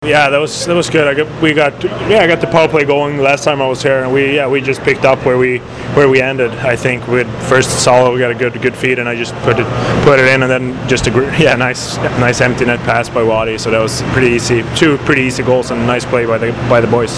After the game I managed to catch up with Dean Evason, Filip Forsberg, and Scott Darling who gave their thoughts on the game.